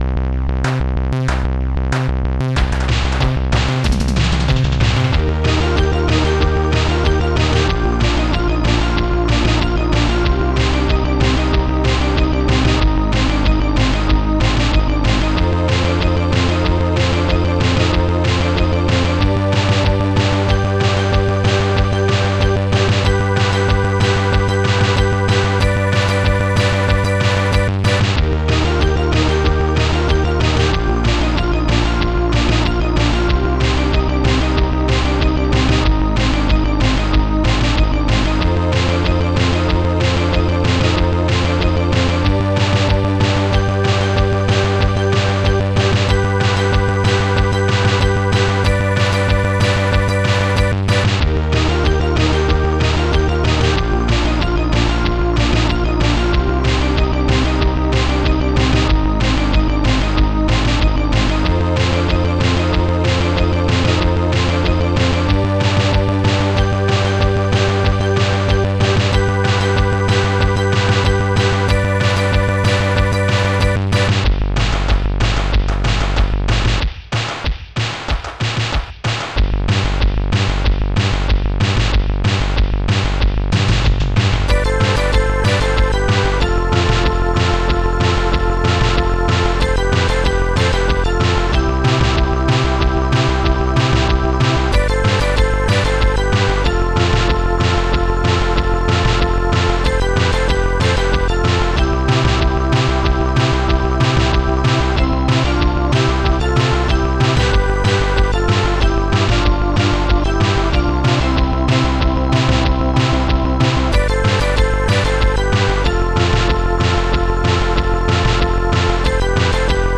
GOOD BASS DRUM HARD SNARE SYNTHDRUM SYNTH PLUCK heaven CLAP